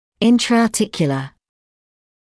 [ˌɪntrəɑː’tɪkjələ][ˌинтрэаː’тикйэлэ]внутрисоставной